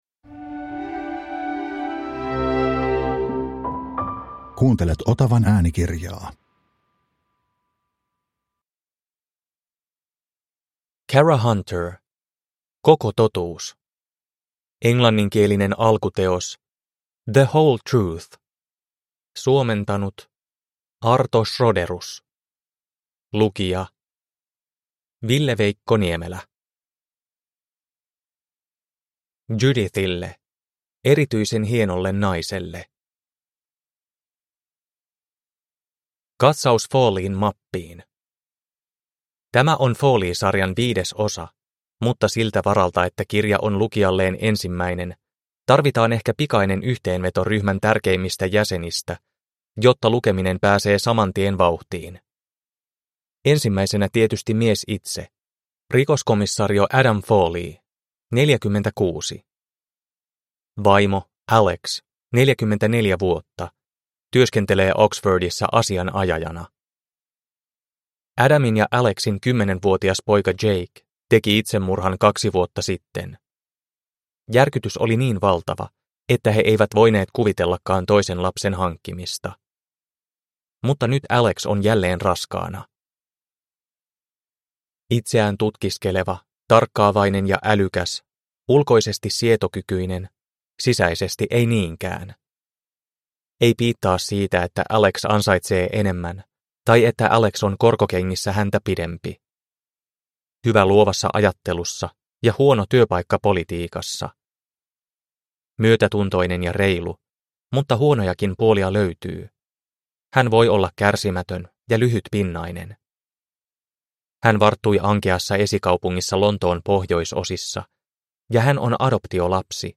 Koko totuus – Ljudbok – Laddas ner